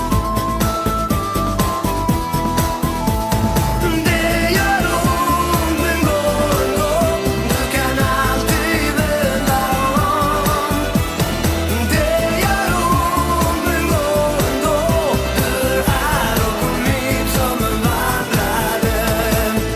Hesa rösten